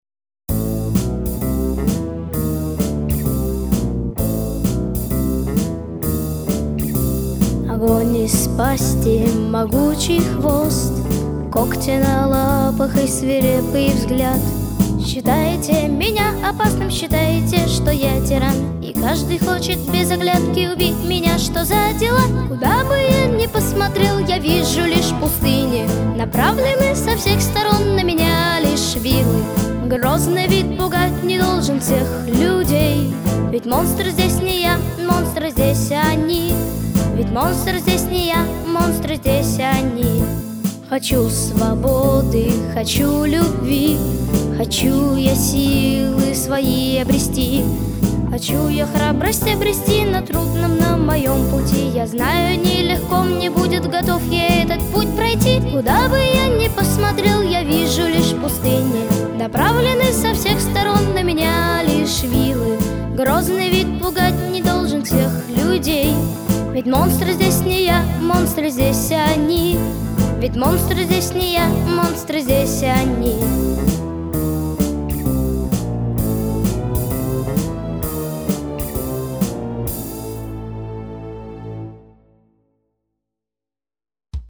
Записано в студии Easy Rider в октябре–декабре 2023 года